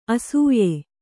♪ asūye